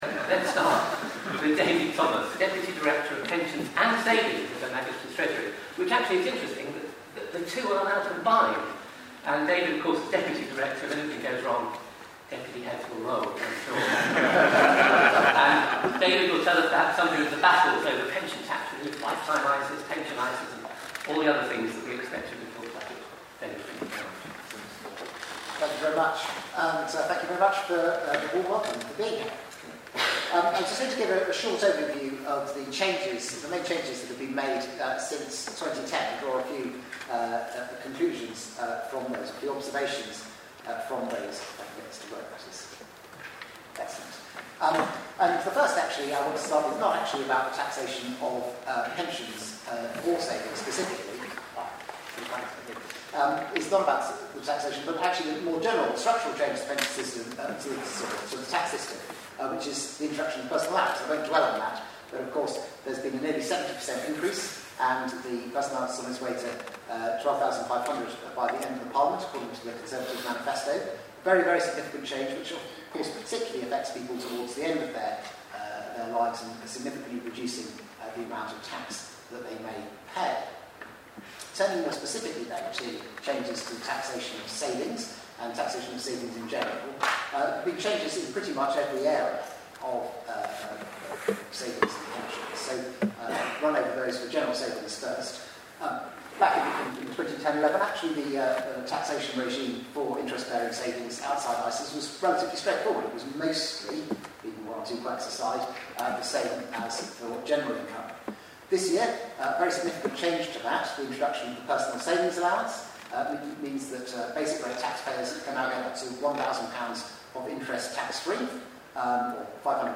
A panel of experts debated the role of the tax system when it comes to pensions and savings, in the latest CIOT/IFS collaboration at the RSA in London. The debate was chaired by the charismatic Paul Lewis, presenter of Radio 4’s Money Box.